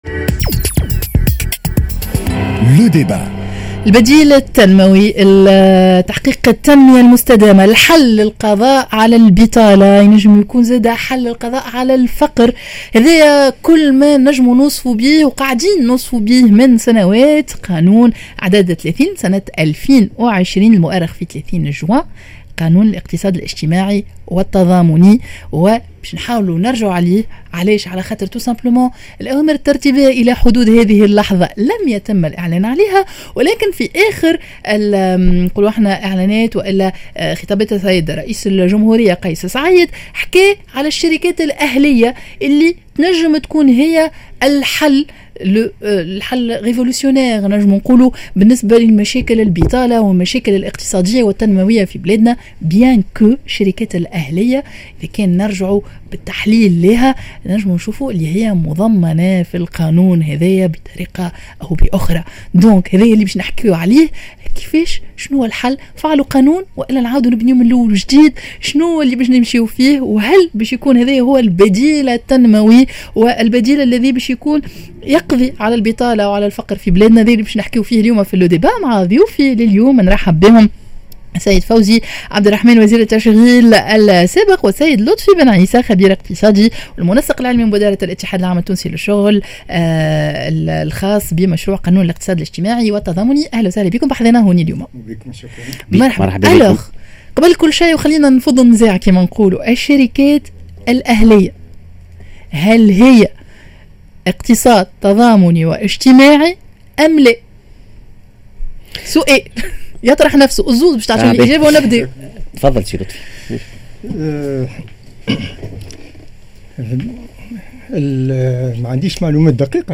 Le débat